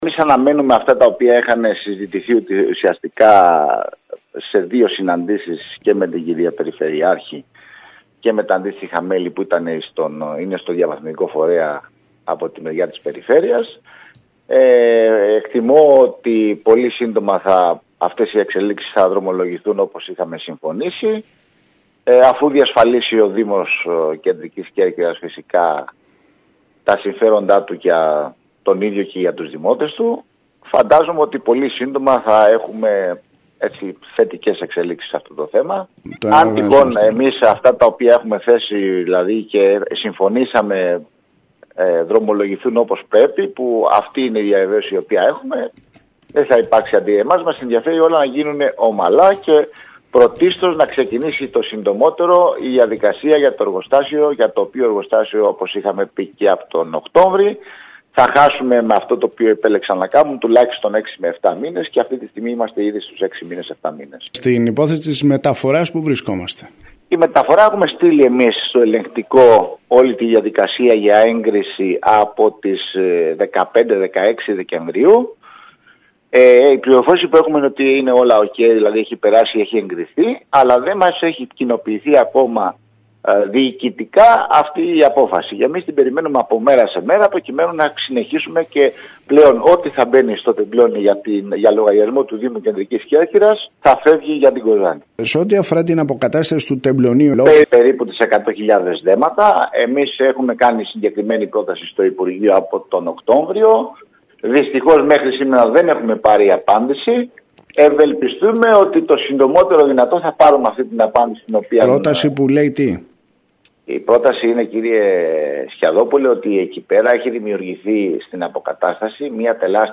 Τη μετάβαση του φορέα διαχείρισης των απορριμμάτων της Κέρκυρας από τον διαδημοτικό ΣΥΔΙΣΑ στο διαβαθμιδικό ΦΟΣΔΑ, ανακοίνωσε μιλώντας στην ΕΡΤ Κέρκυρας, ο Αντιδήμαρχος Καθαριότητας Γιάννης Σερεμέτης.